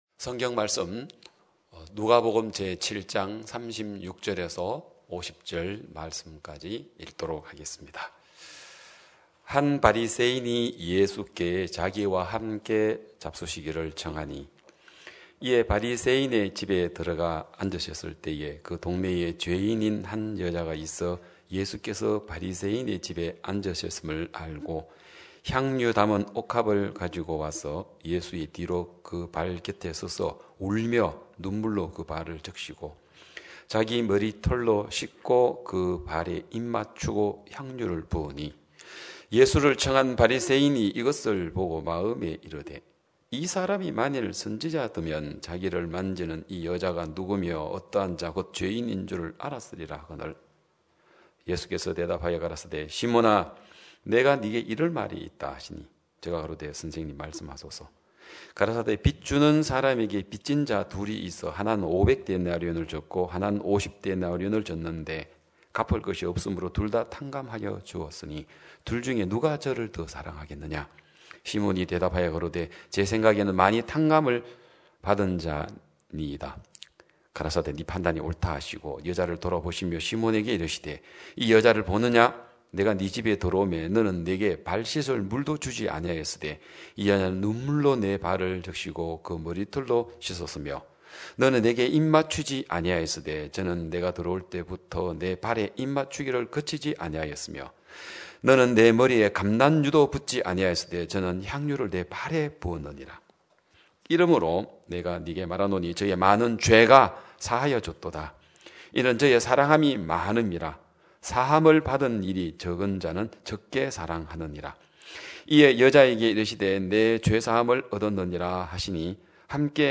2021년 12월 26일 기쁜소식양천교회 주일오전예배
성도들이 모두 교회에 모여 말씀을 듣는 주일 예배의 설교는, 한 주간 우리 마음을 채웠던 생각을 내려두고 하나님의 말씀으로 가득 채우는 시간입니다.